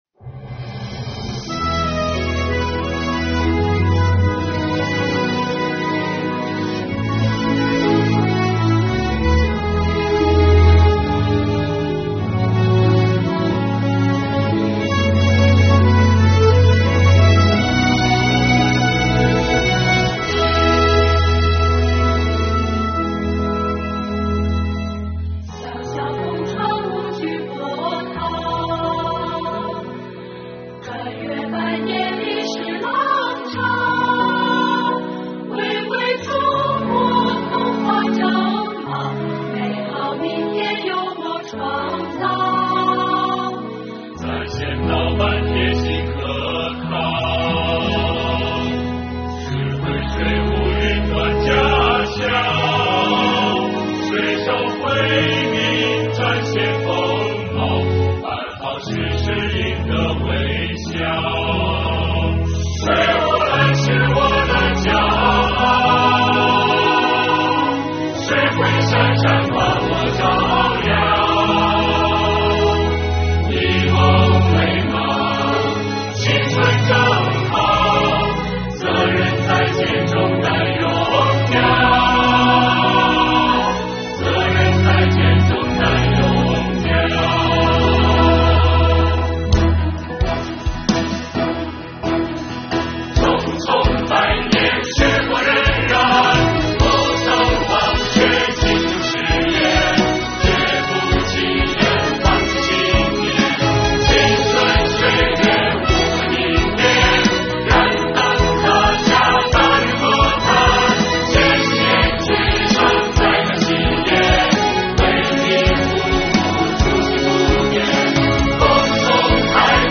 2022年新年伊始，我们用税务人录制的歌曲，为大家制作了一本音乐挂历，并将全年办税时间做了标注，伴着歌声开启新征程吧。